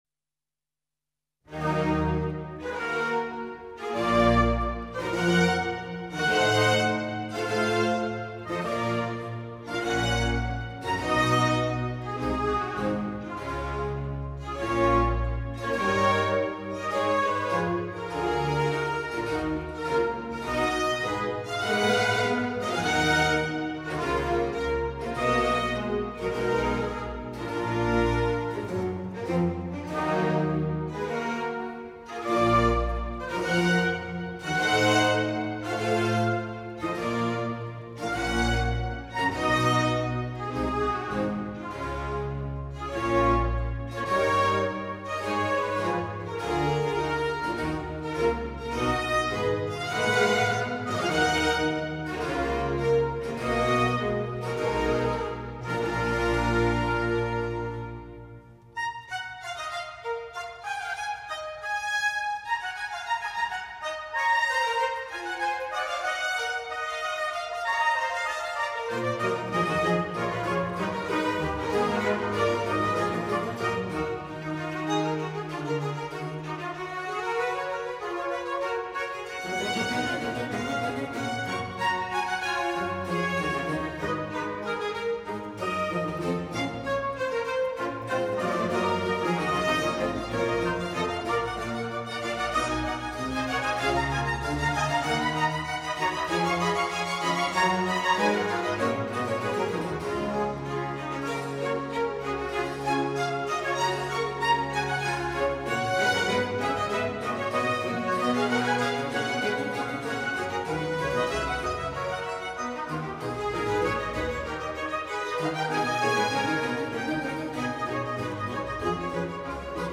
New recordings of Renaissance, Baroque and Classical repertoire have brought many of these ideas to life with performances that are exuberant, captivating, vibrant and joyful.
Academy of Ancient Music, Christopher Hogwood, conductor, 1980.